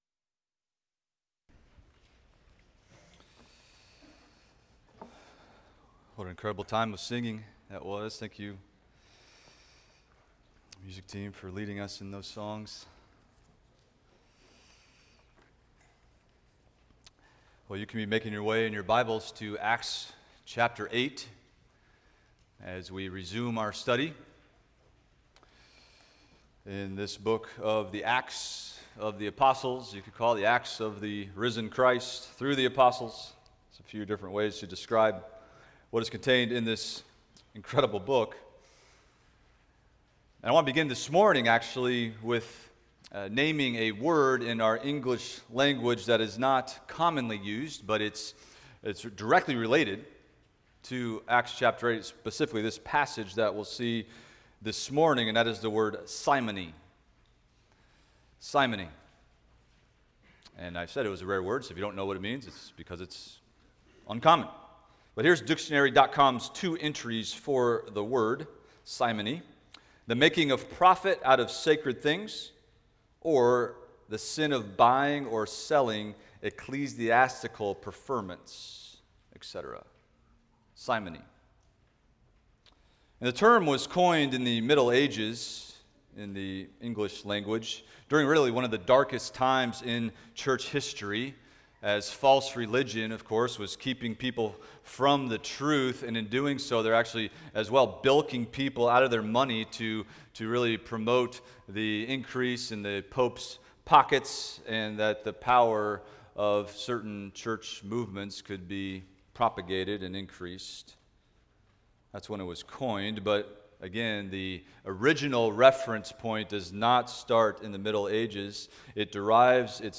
Expository Preaching through the Book of Acts